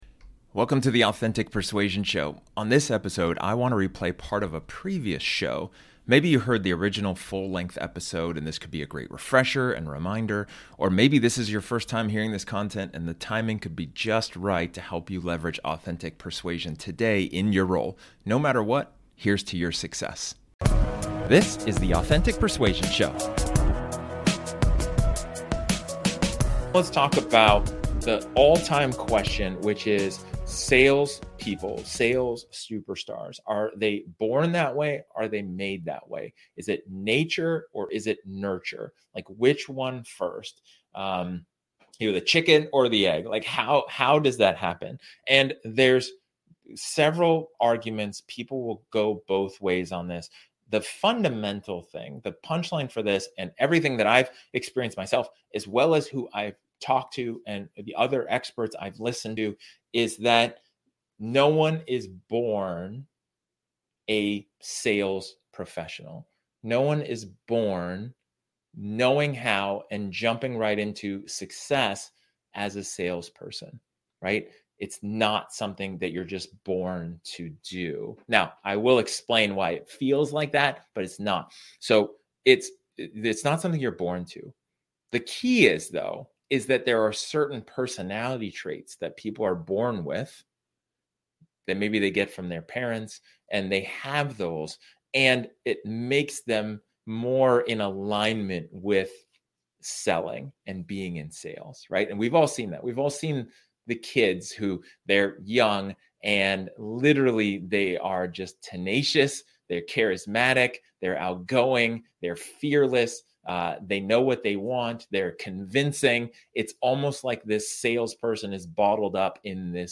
This episode, is an excerpt from one of my training sessions where I talk about the question: "Are Sales Superstars Born or Made?".